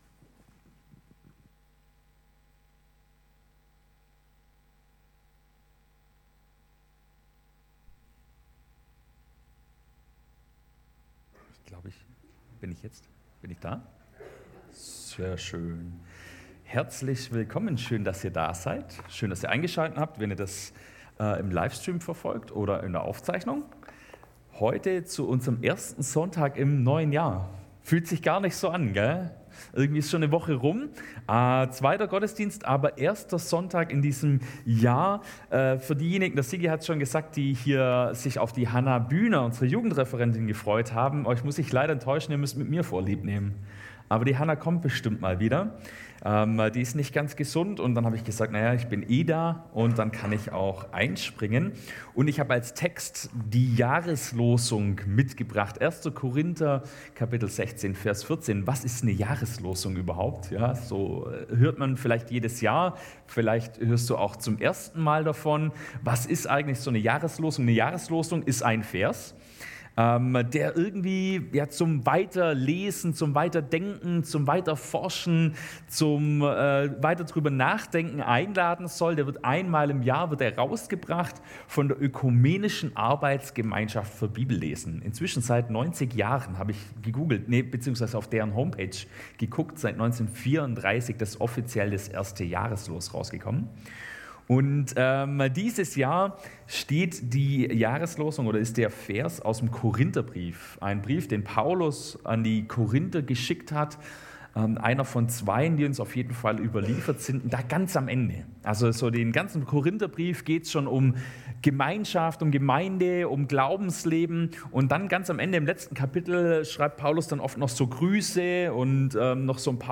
Predigt am 07.01.2024